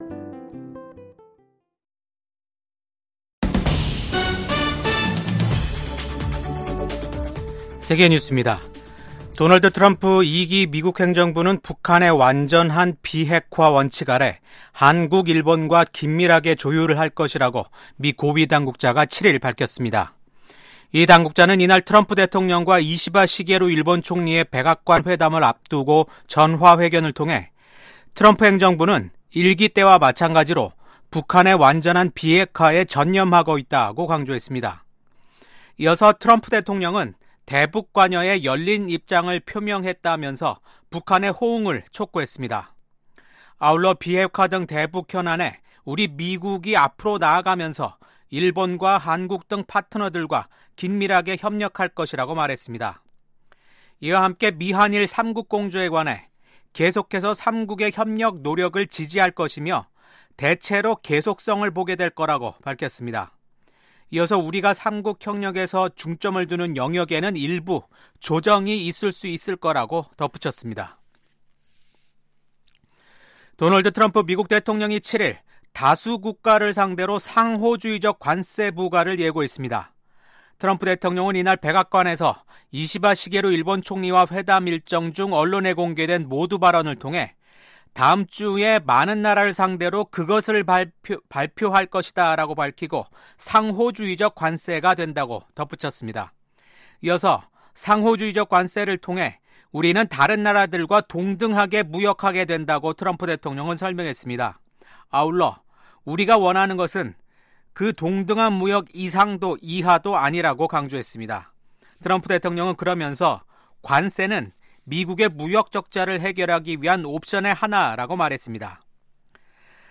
VOA 한국어 아침 뉴스 프로그램 '워싱턴 뉴스 광장'입니다. 미국 도널드 트럼프 대통령의 측근인 빌 해거티 상원의원이 미한일 경제 관계는 3국 협력을 지속시키는 기반이 될 수 있다고 강조했습니다. 한국에서 정부는 물론 방산업체 등 민간기업들까지 중국의 생성형 인공지능(AI) 딥시크 접속 차단이 확대되고 있습니다.